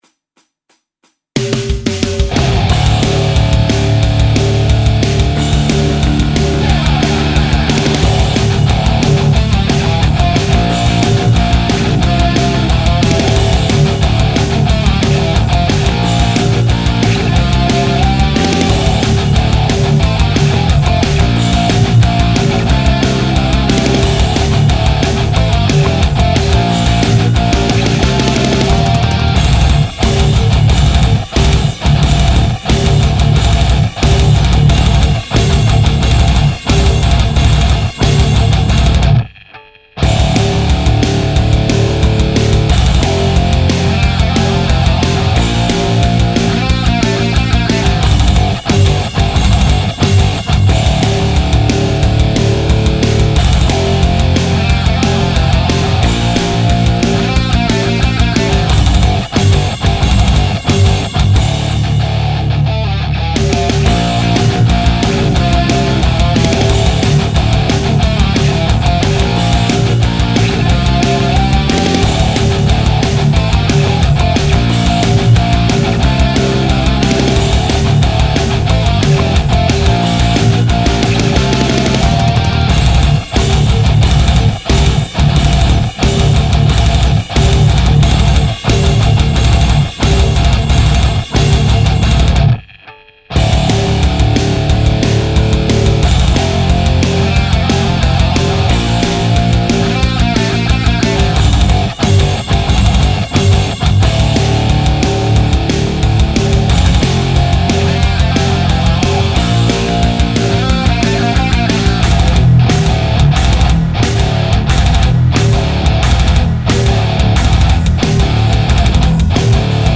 un nouveau petit mix pour vos oreilles averties, un peu différent de mon style de prédilection, mais bon :
Comme d'hab, grattes et basse au podXT (en attendant l'axe-fx), et EZ Drummer pour les batteries.
edit: je trouve les basses hyper envahissantes, pas vous?
Bref elle fait un peu brute et pas assez travaillée pour rentrer dans le mix, même si elle est quand même bien cool.
En attendant baisse tes cymbales elles sont un peu trop fortes par rapport aux fûts. Ton son de guitare est cool sur les palm mutes mais sonne un peu chelou (hyper filtré/typé) sur le reste.
EDIT : la basse sonne un peu comme une prise DI brute de chez brute en fait
edit: le son des grattes est un peu uber compresse en effet, je vais corriger un peu ça...